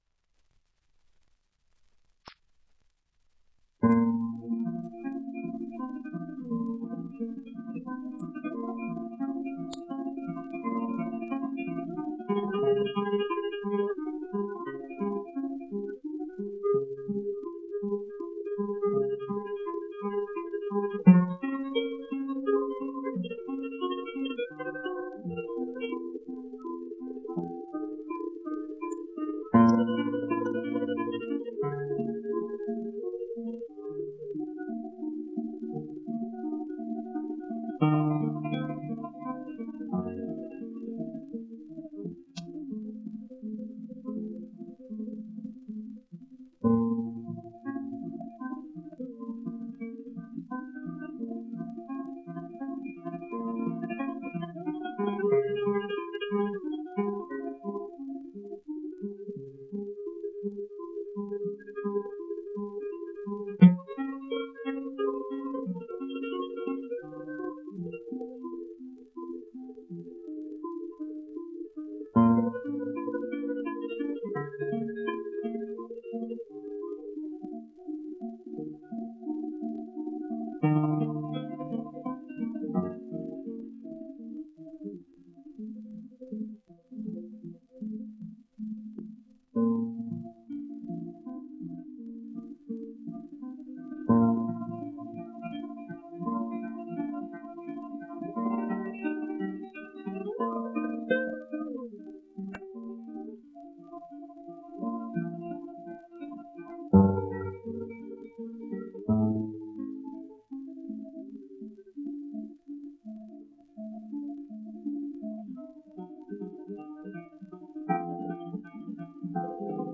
Fandanguillo (sonido remasterizado)